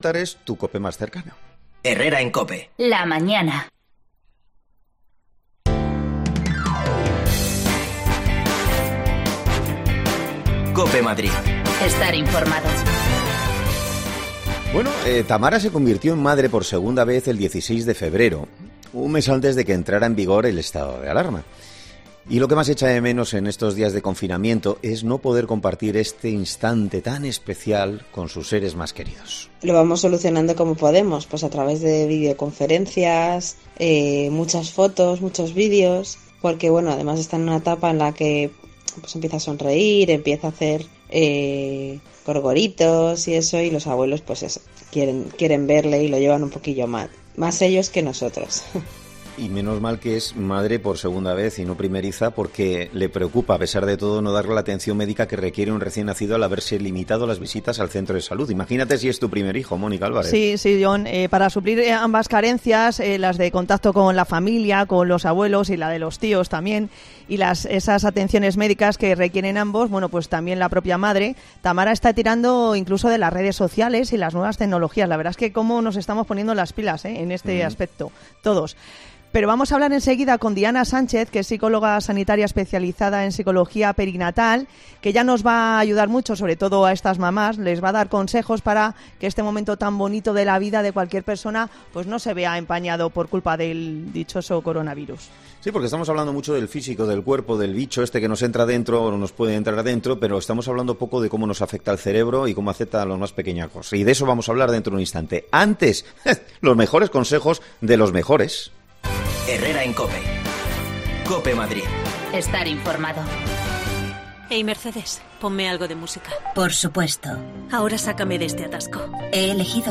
AUDIO: Las madres que acaban de dar a luz también están pasando su particualr cuarentena. Hablamos de sus necesidades con una psicologa perinatal